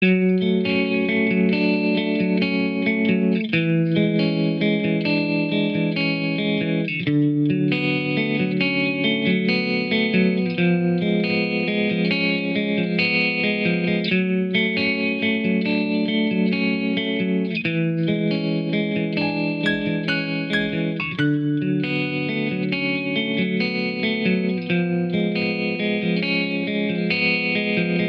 • Качество: 128, Stereo
гитара
лирика
без слов
инструментальные
Спокойный рингтон, чтобы не беспокоить окружающих